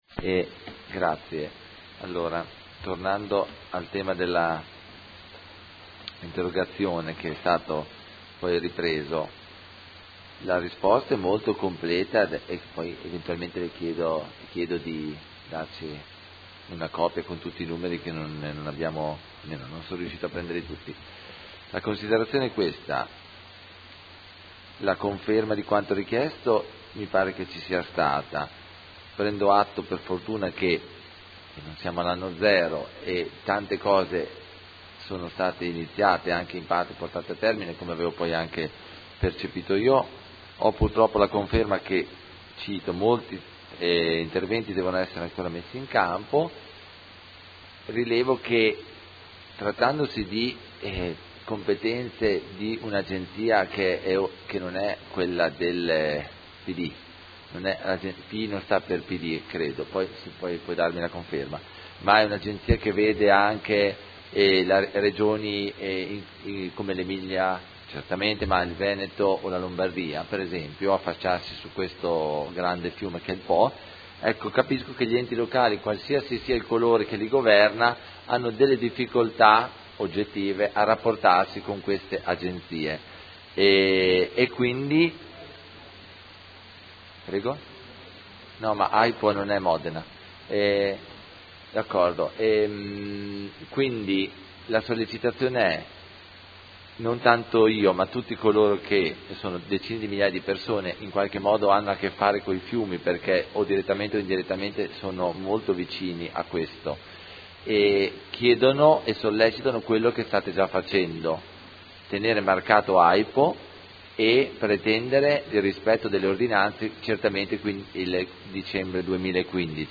Seduta del 22 ottobre. Interrogazione del Consigliere Carpentieri (P.D.) avente per oggetto: Interventi AIPO per la messa in sicurezza degli argini fluviali. Replica